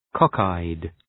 Προφορά
{‘kɒk,aıd}